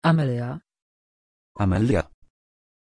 Aussprache von Amélya
pronunciation-amélya-pl.mp3